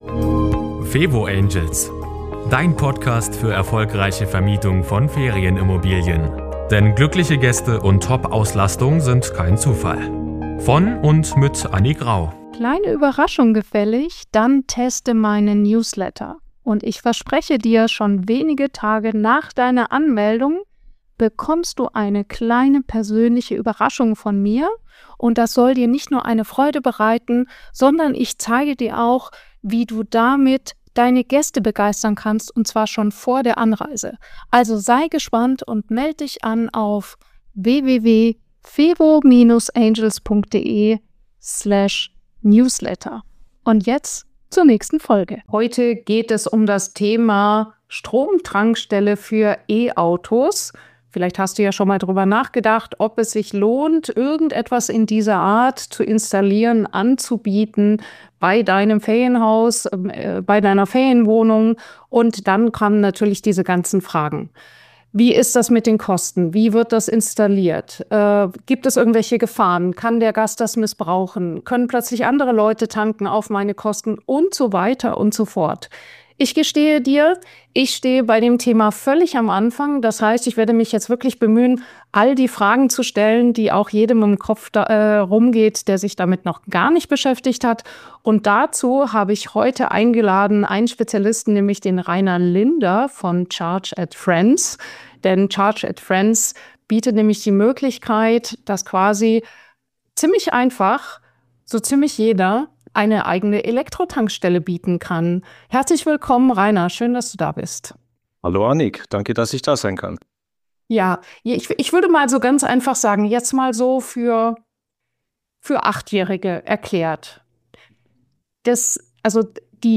Als Interviewgast hörst Du